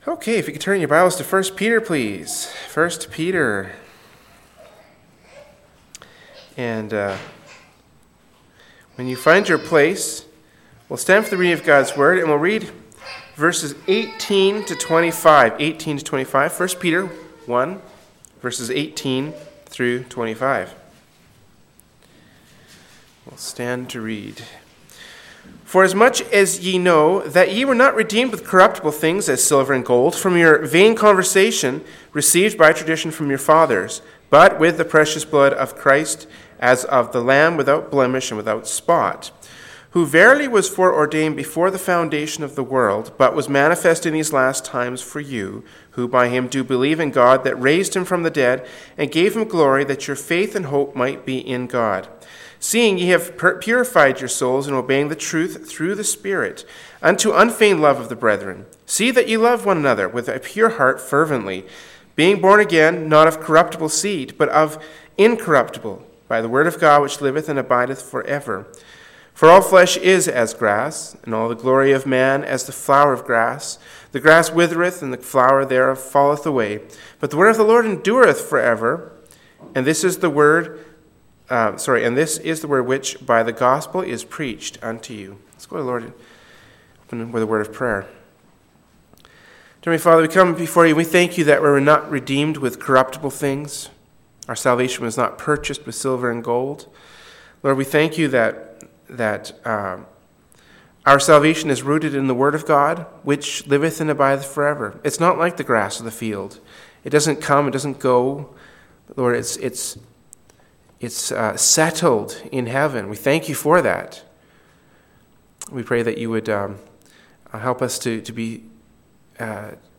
“1st Peter 1:18-25” from Wednesday Evening Service by Berean Baptist Church